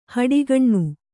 ♪ haḍigaṇ'u